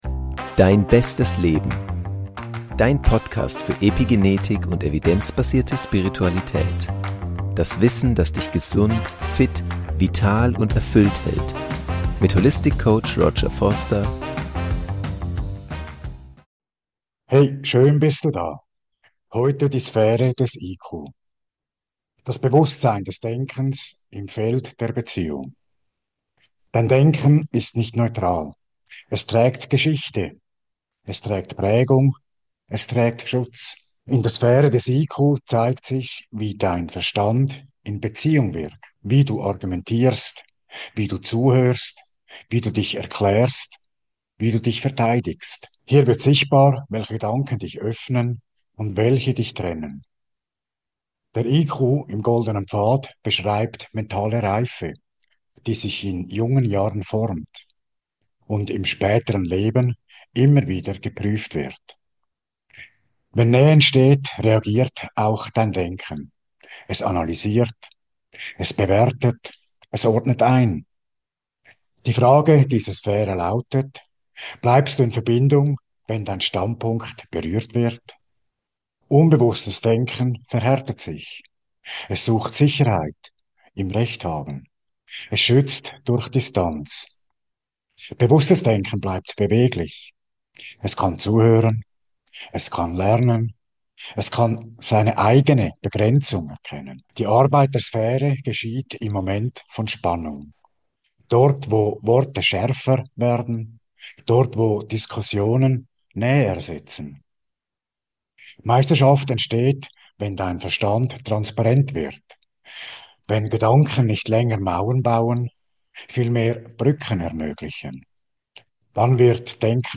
In Gesprächen bei Dir bleiben | Mentale Reife & Klarheit | Tiefe Meditation ~ Dein bestes Leben: Evidenzbasierte Spiritualität und Epigenetik Podcast
Für eine tiefere Erfahrung empfehle ich Kopfhörer und einen ungestörten Moment.